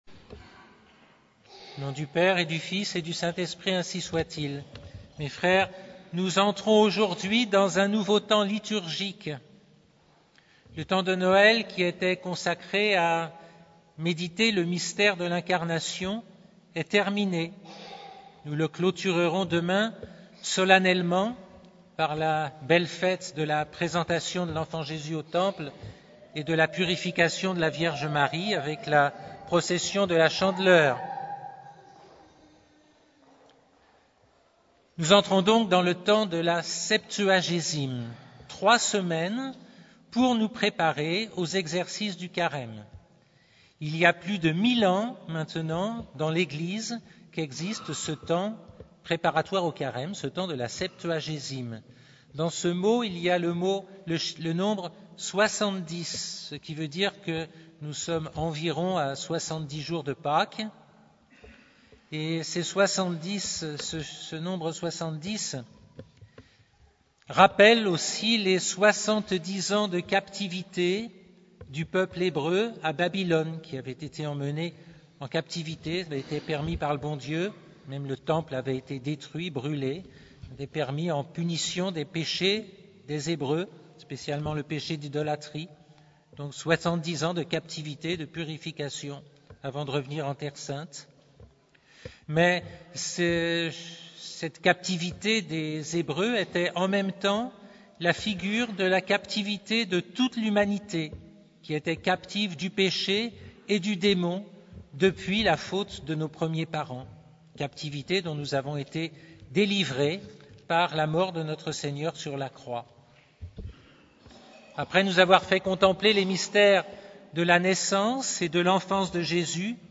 Occasion: Dimanche de la Septuagésime
Type: Sermons